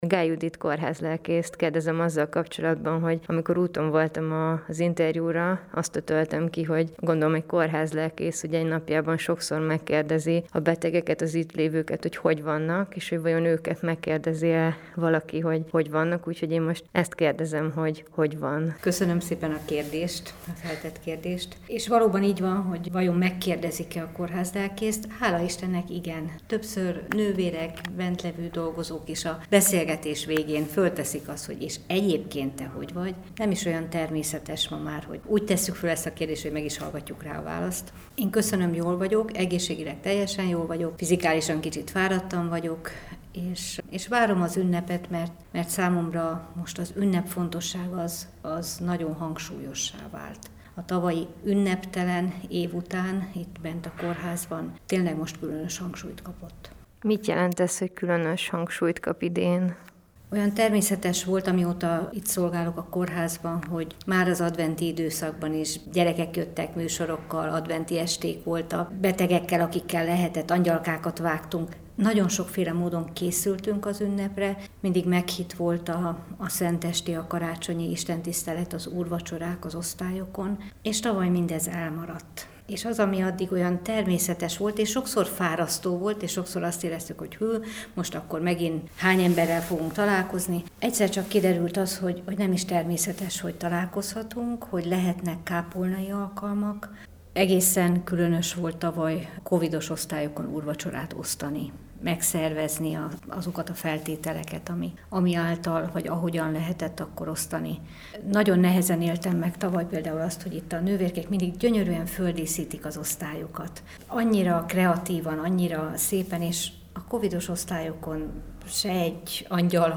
Amikor úton voltam az interjúra, azt ötöltem ki, hogy egy kórházlelkész napjában sokszor megkérdezi a betegeket, az ittlévőket, hogy hogy vannak – és vajon tőlük megkérdezik-e ugyanezt?